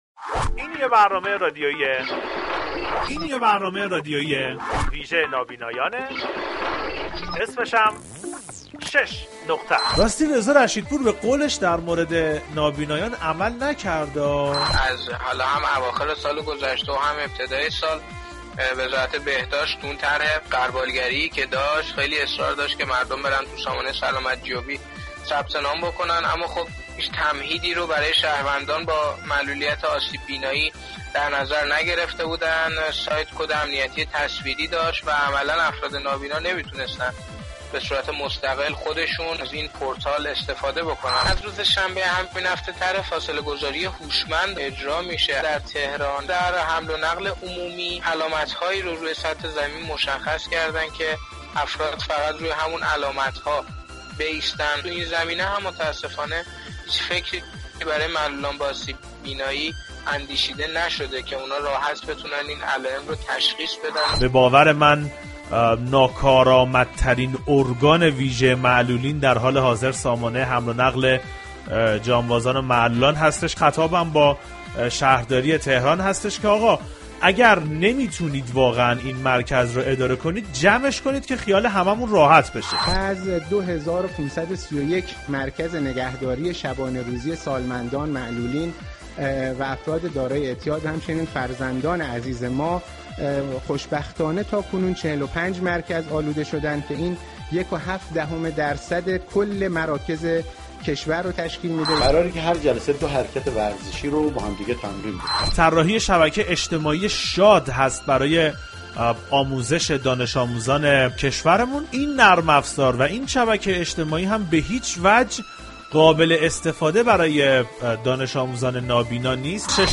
در این برنامه مشكلات نابینایان را با گسترش ویروس كرونا خواهیم شنید و پای صحبت های نابینایان در خصوص مشكلاتی كه این روزها با كرونا دارند می نشینیم.